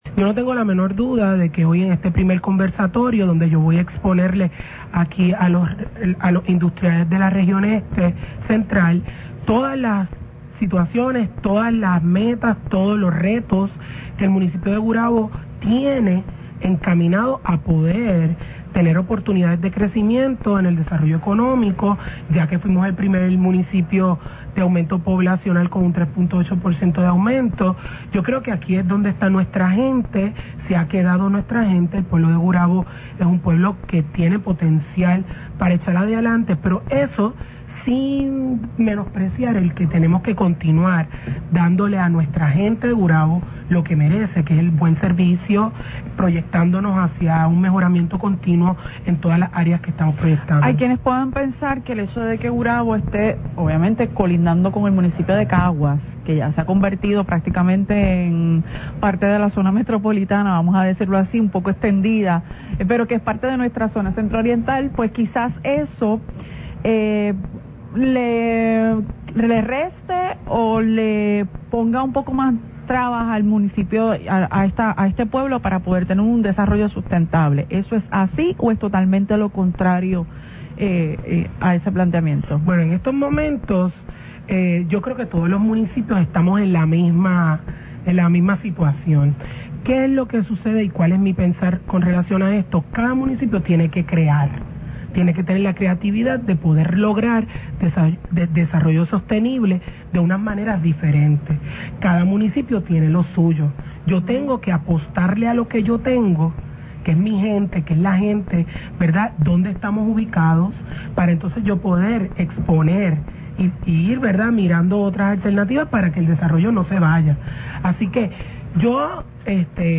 Escuche lo que comentó la Alcaldesa: